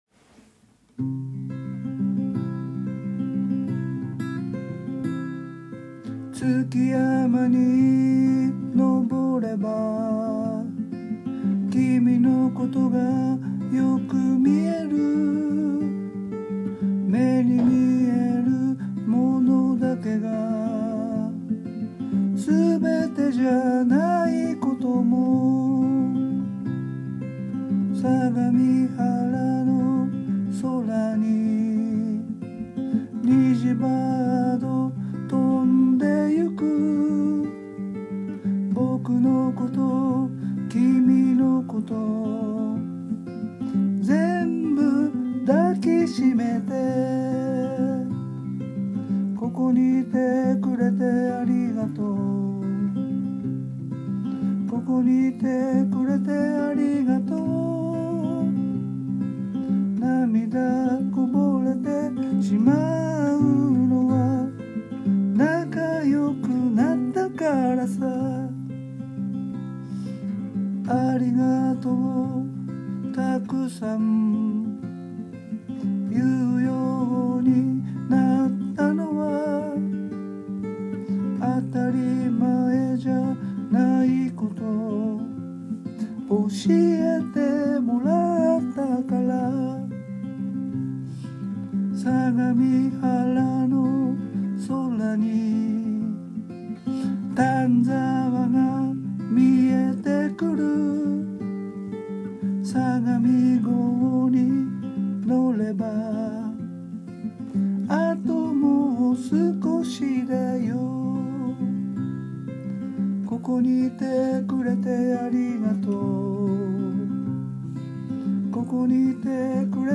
歌・ギター